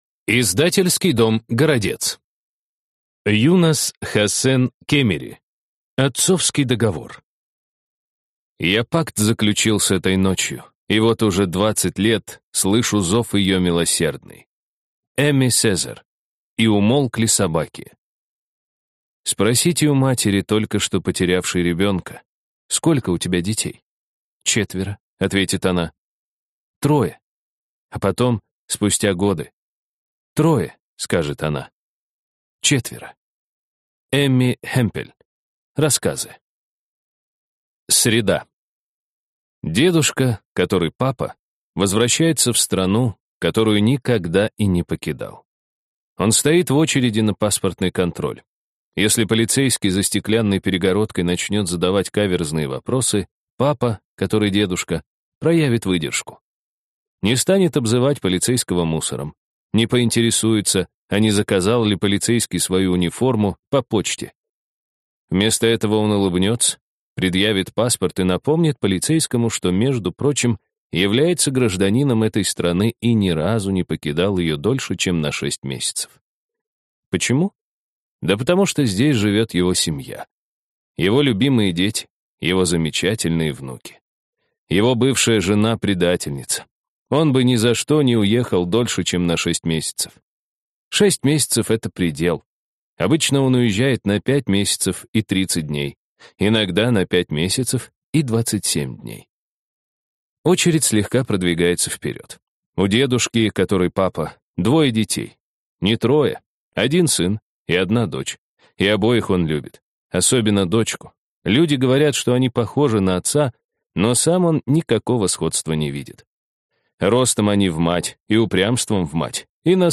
Аудиокнига Отцовский договор | Библиотека аудиокниг